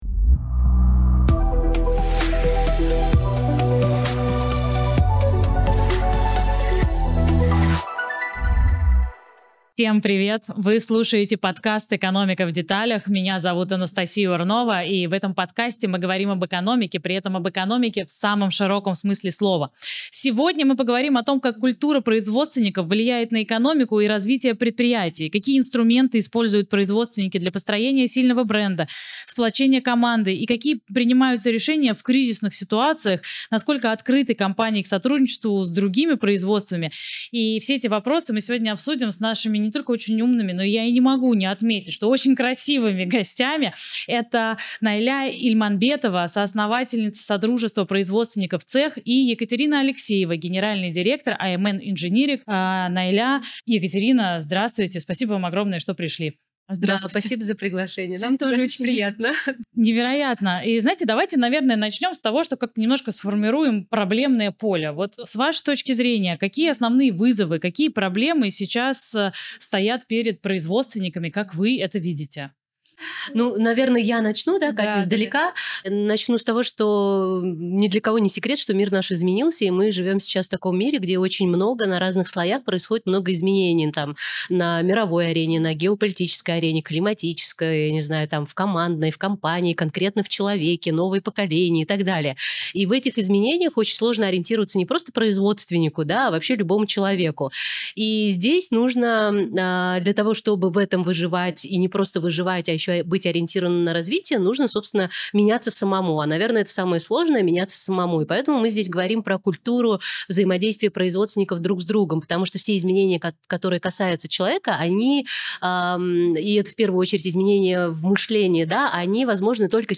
Подкаст «Экономика в деталях» — цикл бесед об устройстве городской экономики и о грамотном подходе к жизни и самореализации в мегаполисе.